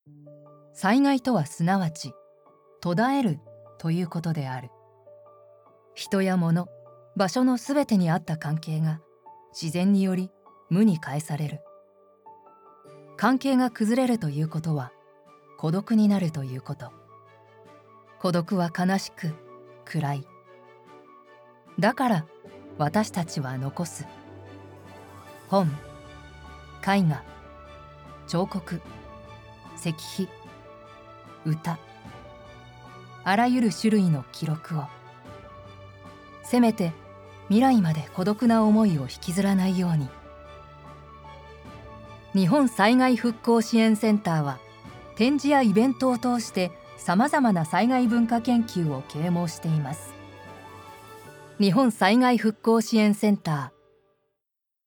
シリアス_災害_希望　災害復興支援センターCM